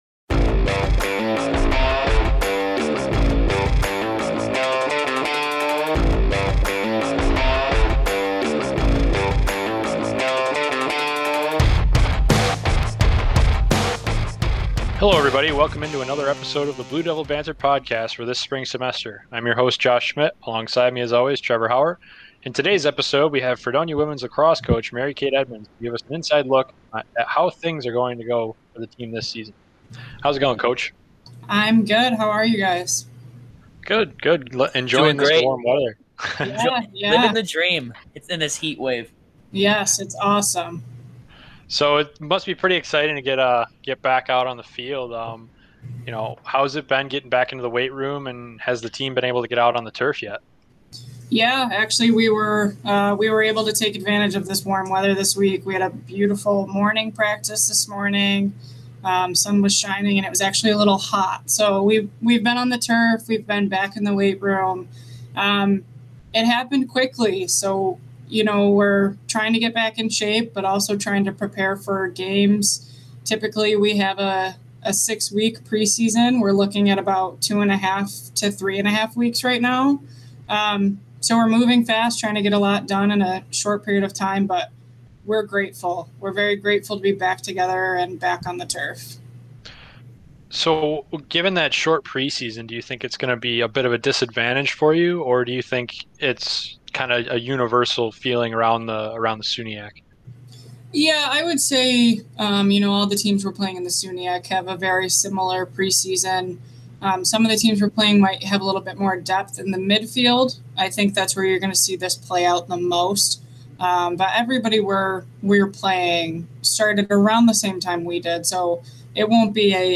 women_lax_interview_blue_devil_banter_mixdown.mp3